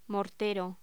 Locución: Mortero
voz